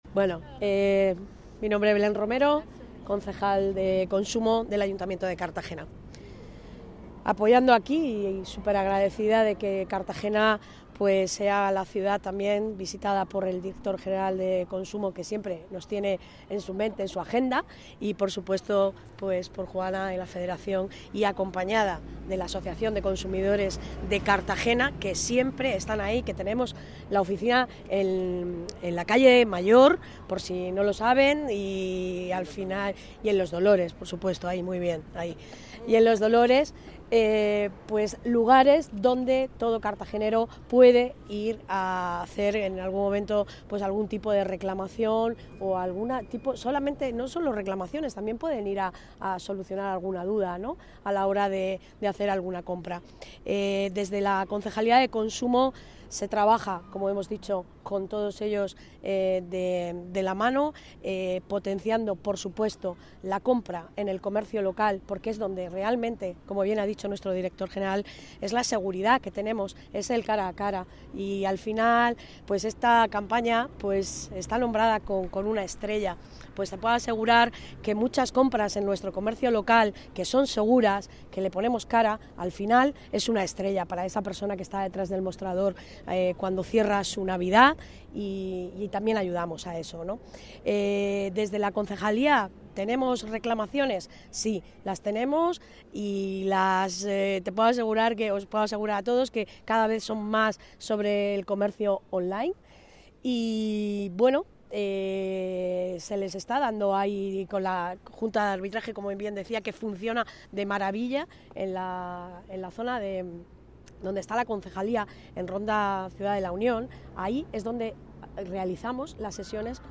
La edil de Comercio, Belén Romero , ha presentado esta campaña denominada 'Consumo en Navidad' ante los medios de comunicación en el mercado navideño instalado en la Alameda de San Antón.